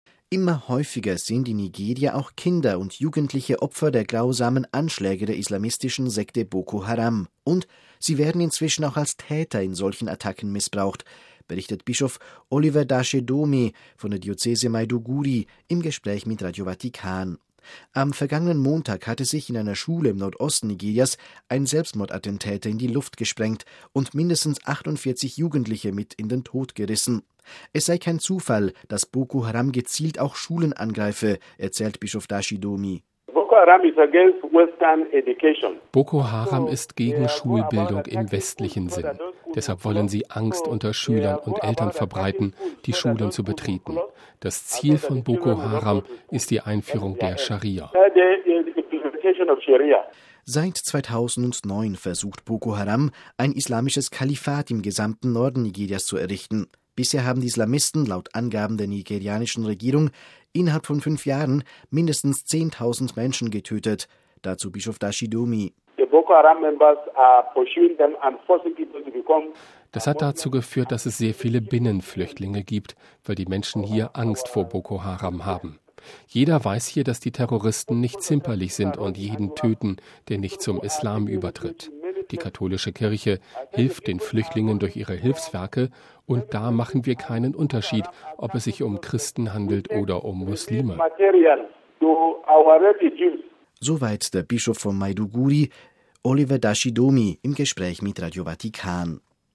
MP3 Immer häufiger sind in Nigeria auch Kinder und Jugendliche Opfer der grausamen Anschläge der islamistischen Sekte Boko Haram. Und: Sie werden inzwischen auch als Täter in solchen Attacken missbraucht, berichtet Bischof Oliver Dashe Doeme von der Diözese Maiduguri im Gespräch mit Radio Vatikan.